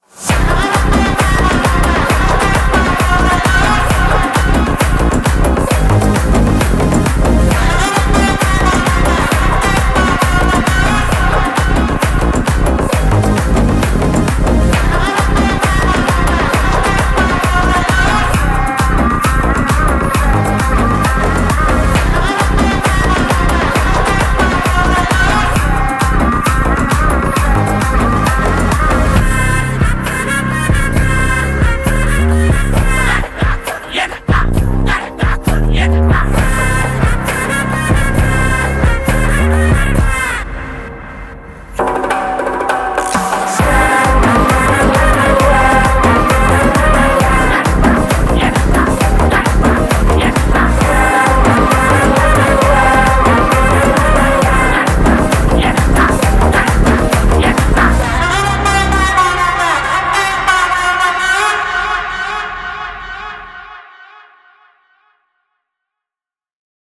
without dialogues and unwanted disturbances